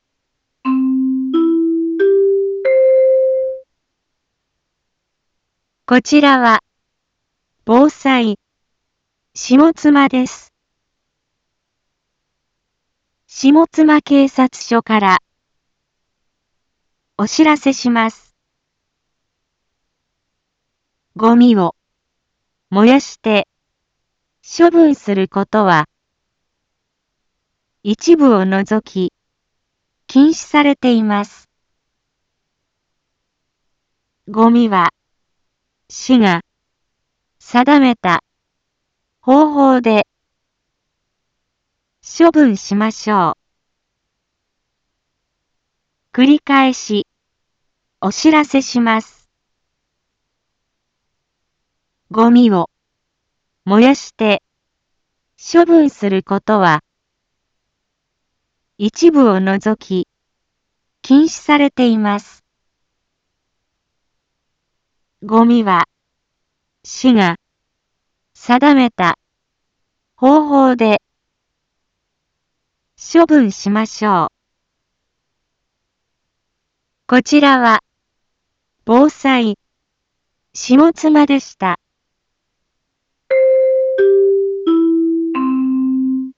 Back Home 一般放送情報 音声放送 再生 一般放送情報 登録日時：2022-07-25 10:01:30 タイトル：ごみの野焼き禁止（啓発放送） インフォメーション：こちらは、防災、下妻です。